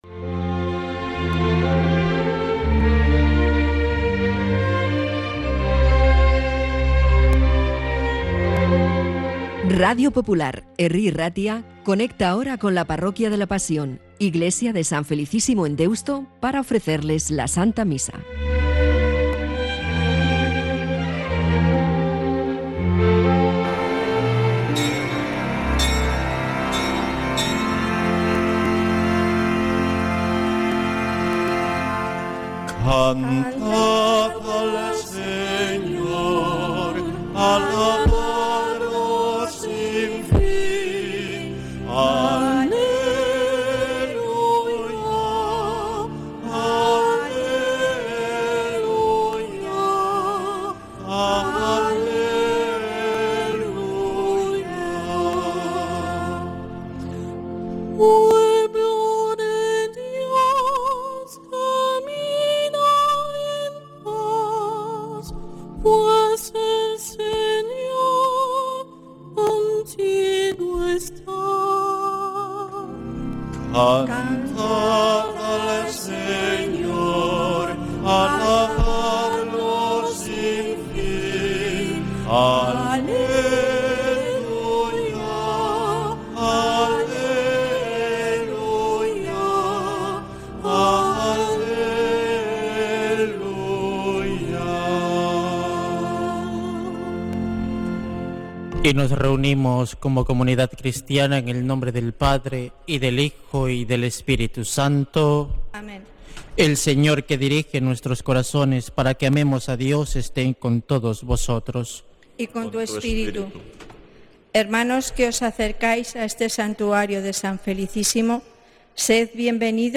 Santa Misa desde San Felicísimo en Deusto, domingo 27 de julio de 2025